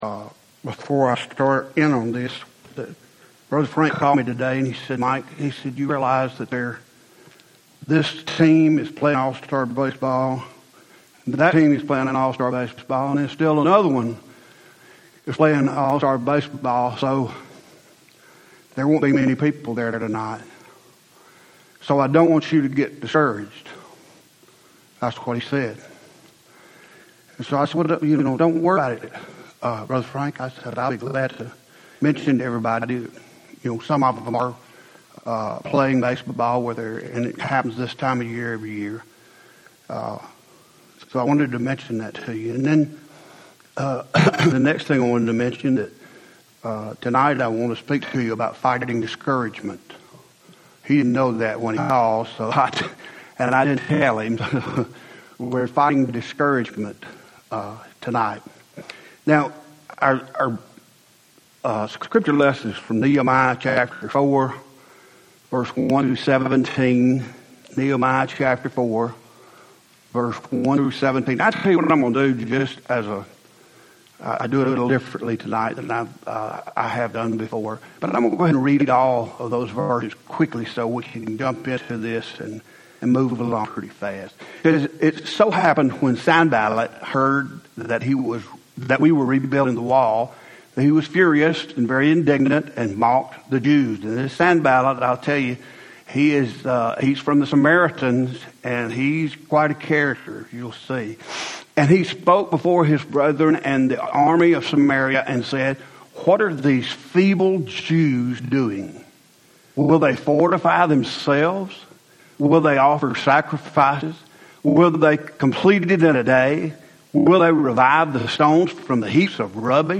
Home › Sermons › Fighting Discouragement